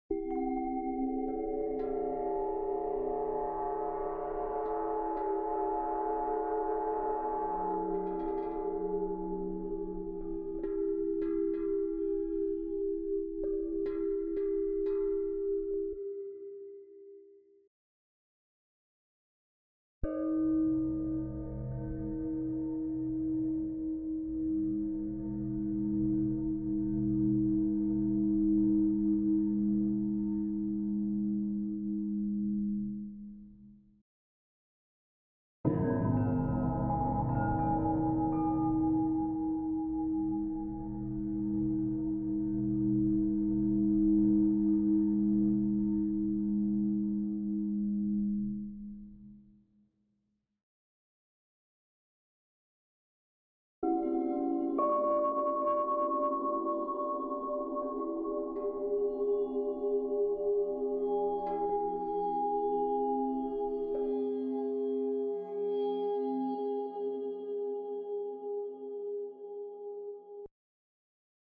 magic-metal-ring-aura-white-shimmer-variations.wav